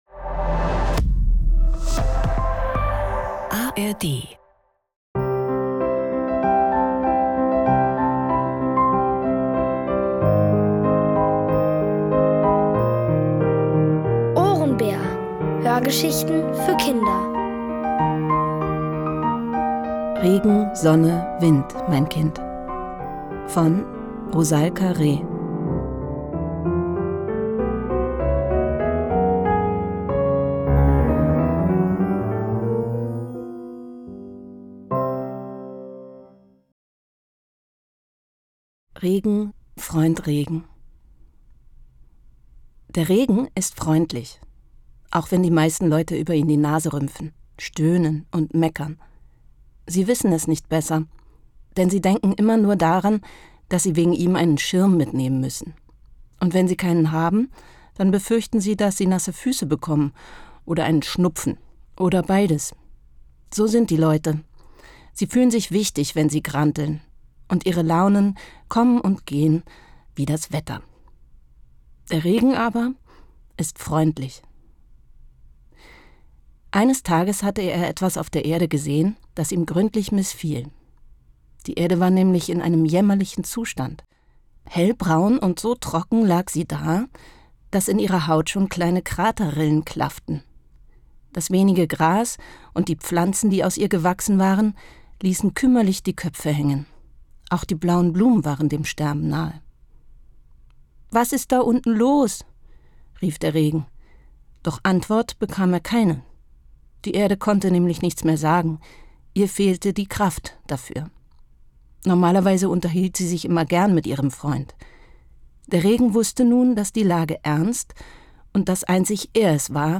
Es liest: Maren Eggert.